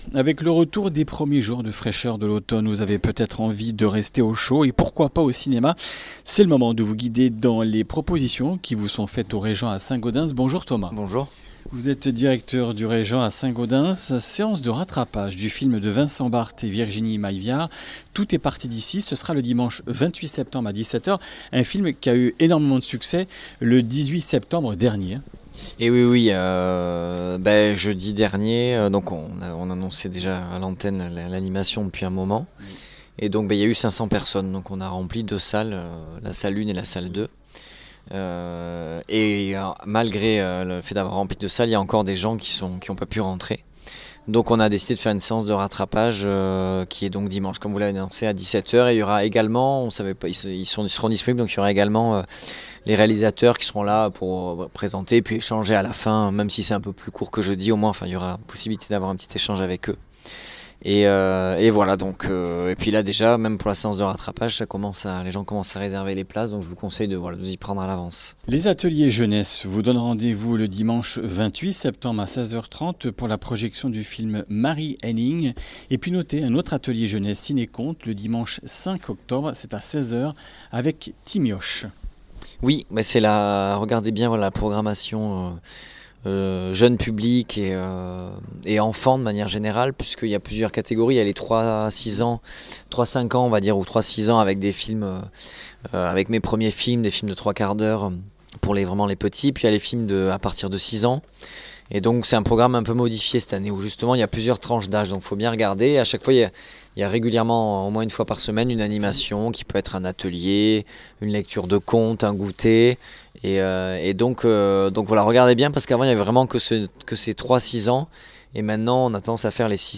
Comminges Interviews du 25 sept.